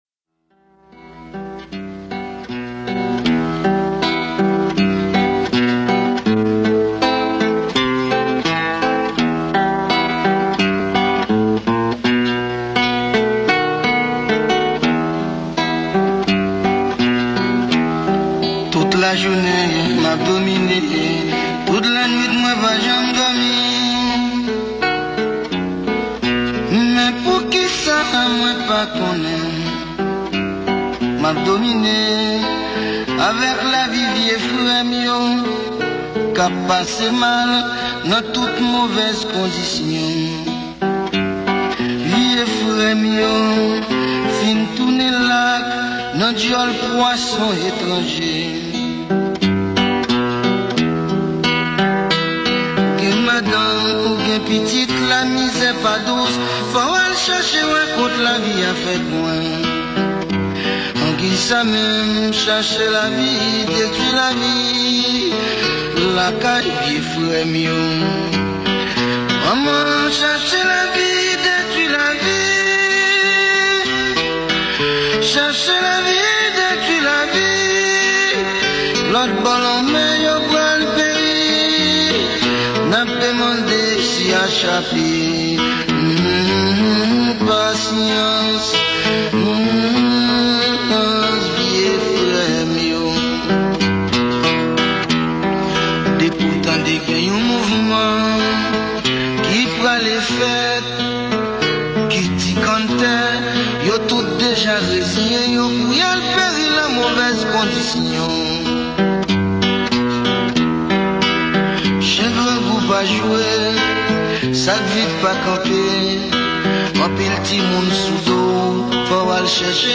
Genre: BOLERO